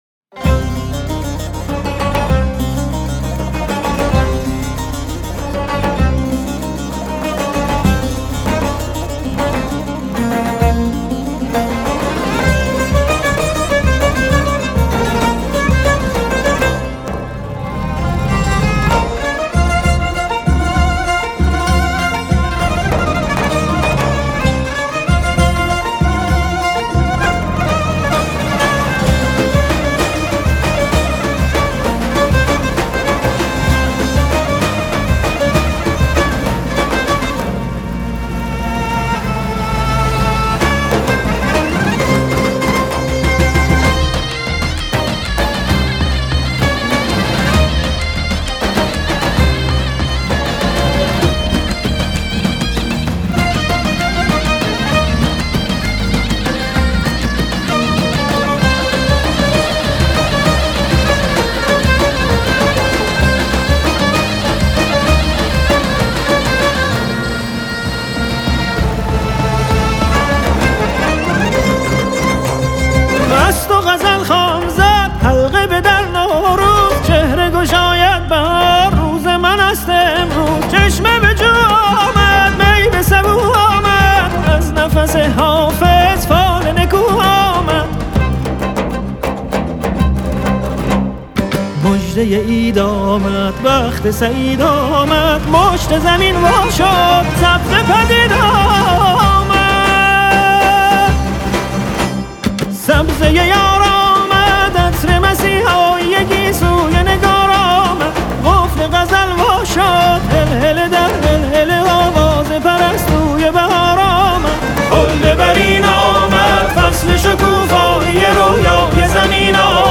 Эрон мусиқаси